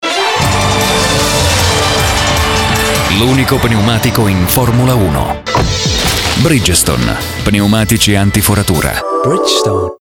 Spot Bridgestone Spot Bridgestone